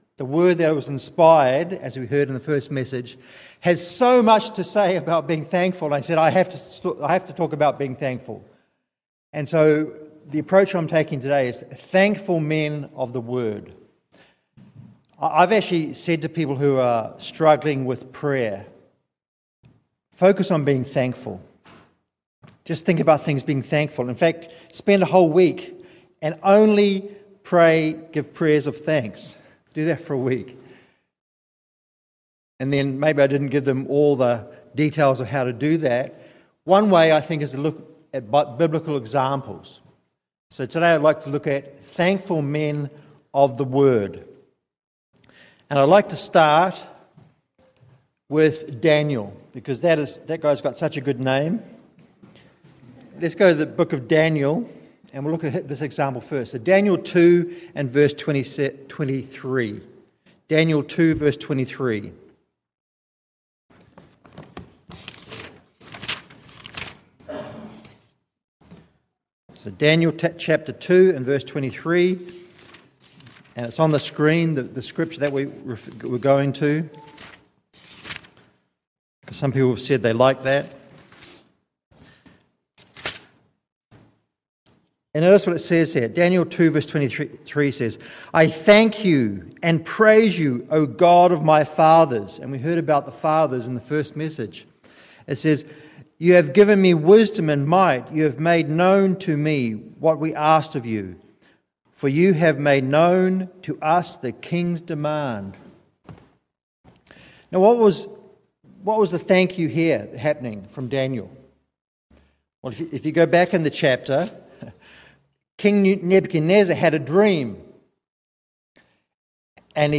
Given in Tacoma, WA Olympia, WA